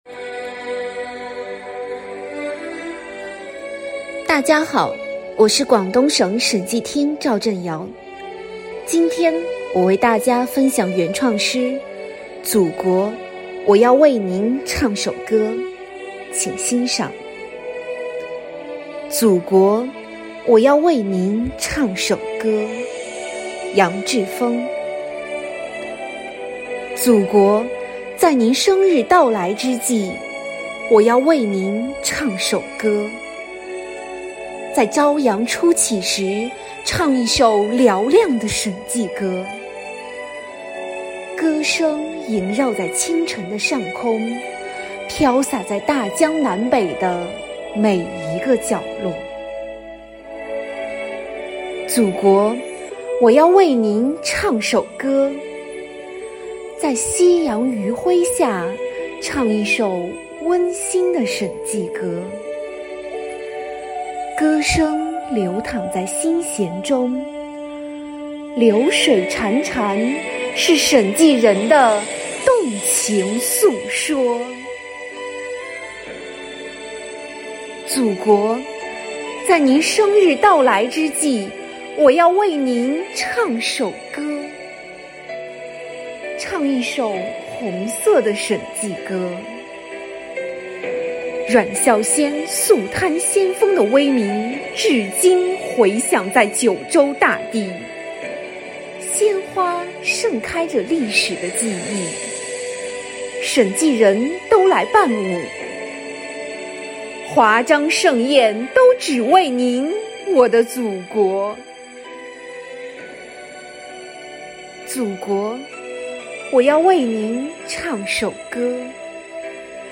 今天我们推出第一期，来自广东审计人的诗歌朗诵作品，以诗传情。